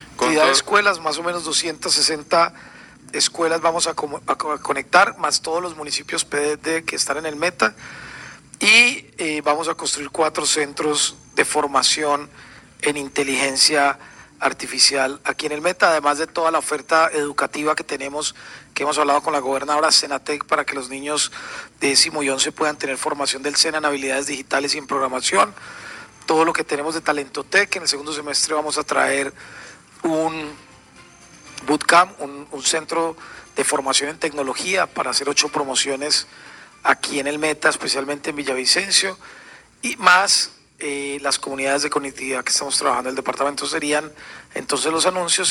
☝🏻Audio ministro de las Tecnologías de la Información y la Comunicación, Mauricio Lizcano.
Esto, más la oferta educativa del ministerio como SenaTec dirigido a estudiantes de décimo y once de bachillerato, y Talento TIC, que consiste en un Centro de Formación en Tecnología, de acuerdo con lo dicho por el ministro Lizcano, en rueda de prensa.